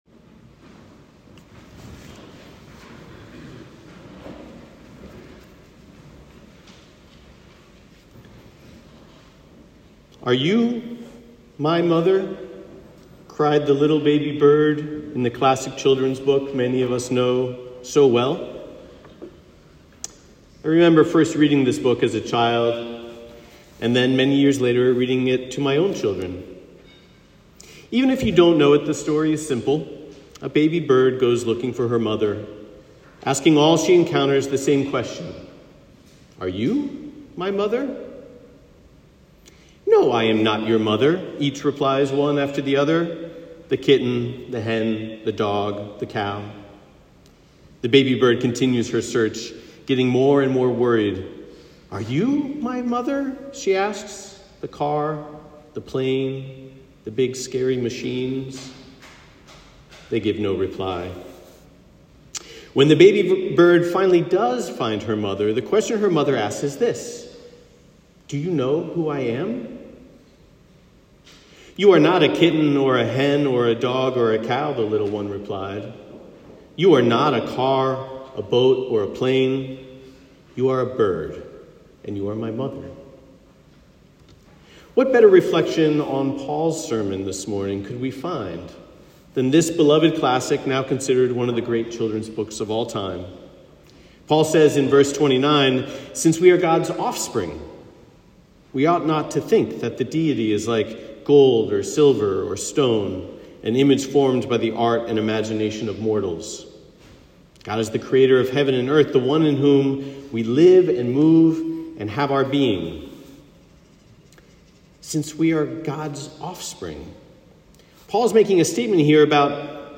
Are you my mother - Sermon.m4a